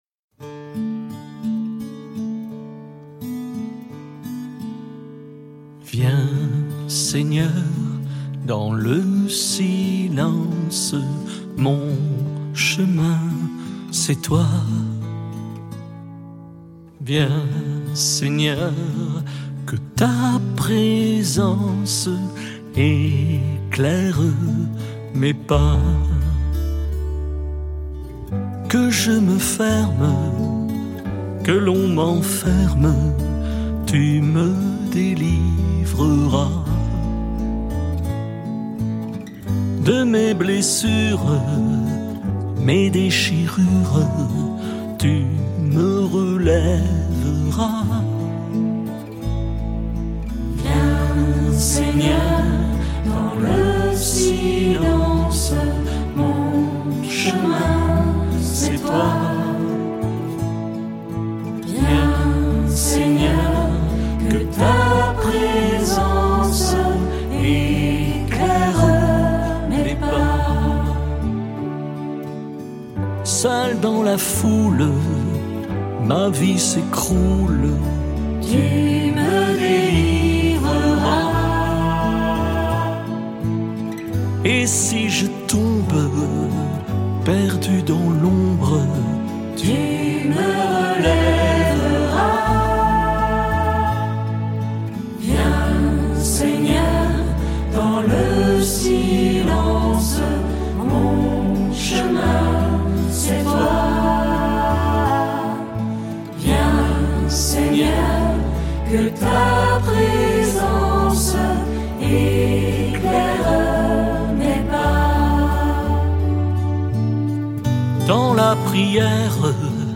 La prière chantée de la semaine